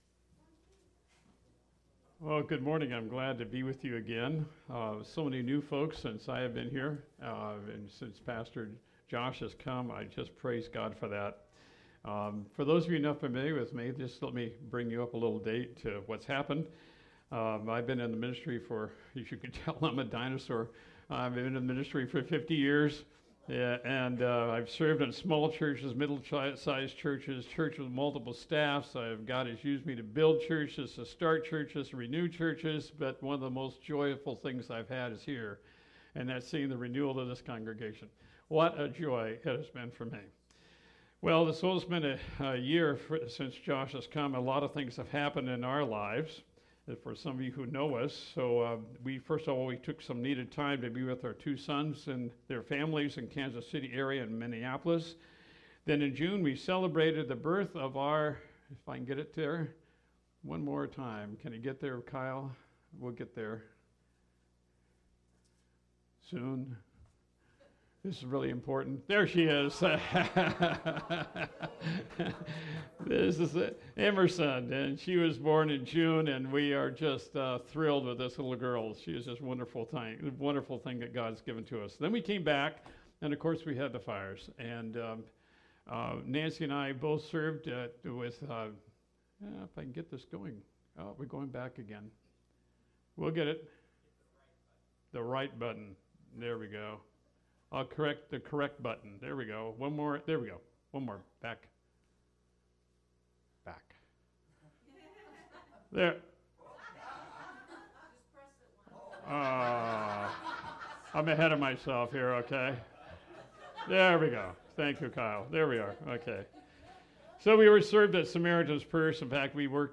Special Sermon